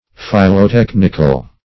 Search Result for " philotechnical" : The Collaborative International Dictionary of English v.0.48: Philotechnic \Phil`o*tech"nic\, Philotechnical \Phil`o*tech"nic*al\, a. [Philo- + Gr. te`chnh an art: cf. F. philotechnique.]